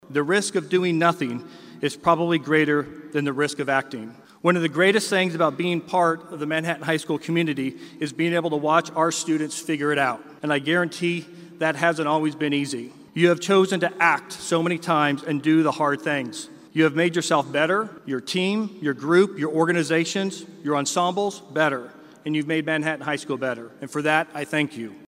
Manhattan High School’s class of 2024 was celebrated Sunday afternoon at Bramlage Coliseum.